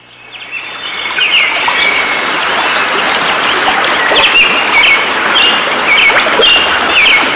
Birds By River Sound Effect
Download a high-quality birds by river sound effect.
birds-by-river-1.mp3